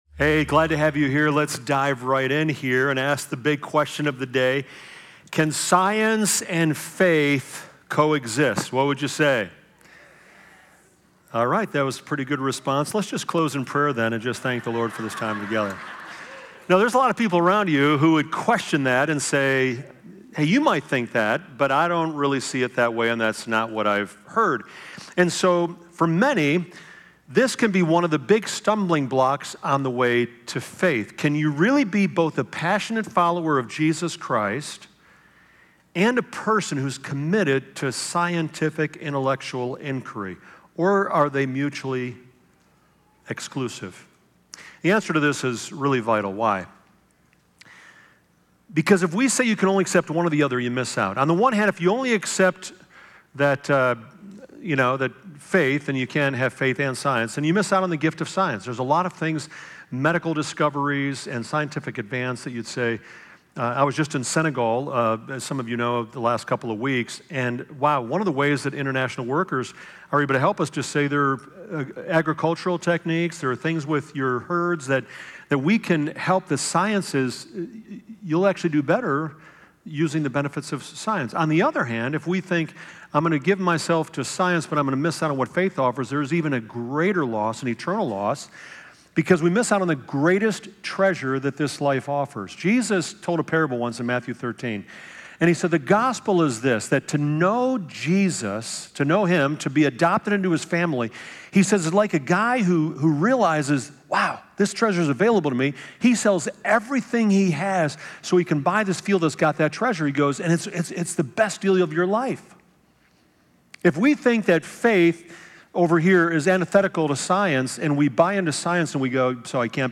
The message navigates through philosophical and scientific discussions, including the cosmological and teleological arguments. These arguments challenge us to consider the origins of the universe and the intricate design of creation, suggesting a deliberate Designer behind the complexities of life. A particularly striking moment in the sermon is the discussion on how even renowned scientists like Richard Dawkins acknowledge the cultural impact of Christianity, hinting at the universal longing for meaning beyond the empirical.